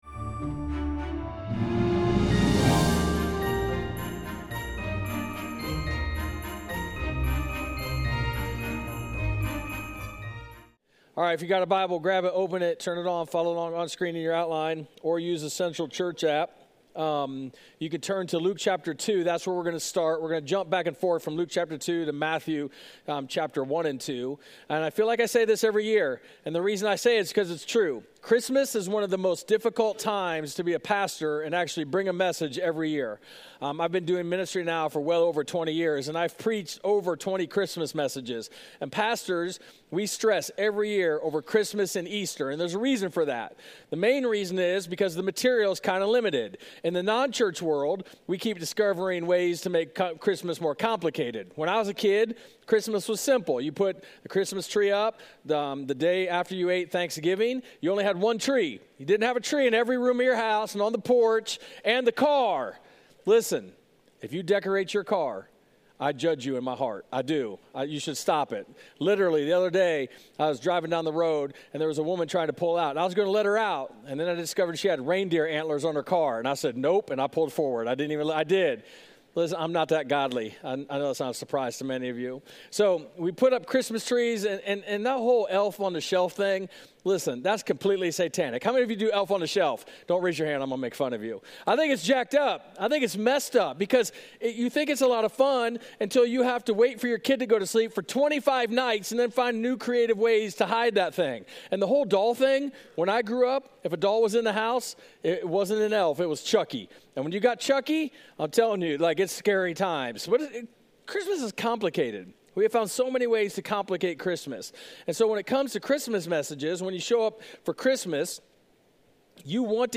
Merry Christmas and welcome to Central Church!